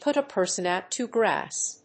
アクセントpùt a person óut to gráss